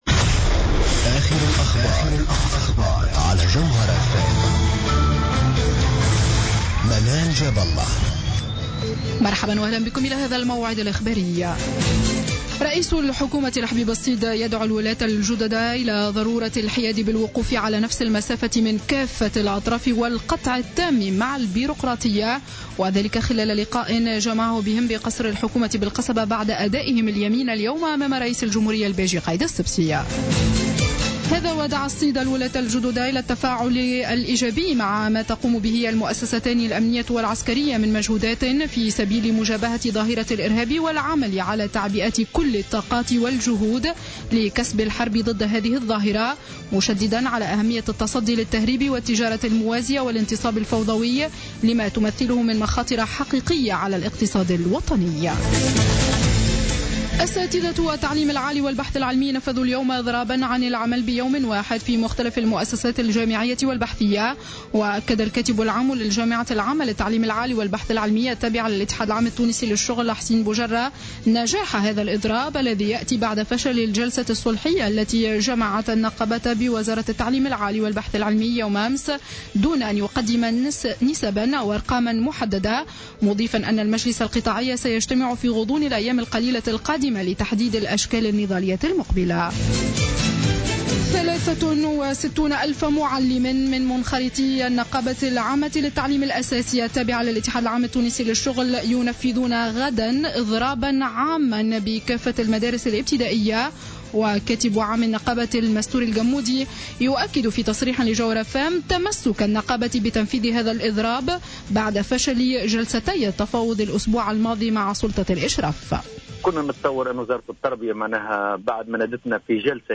نشرة أخبار السابعة مساء ليوم الثلاثاء 14 أفريل 2015